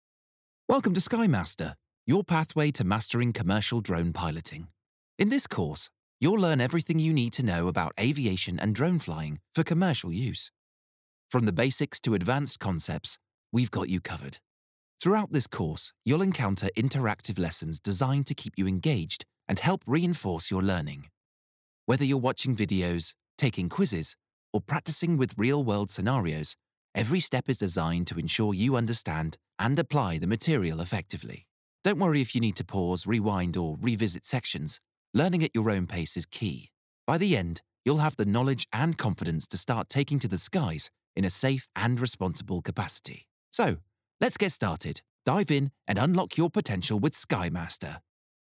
British Voice Over Artist
Utilising a professional home studio setup, I am able to provide not only fast deliverables, but clean and high quality audio.
eLearning Demo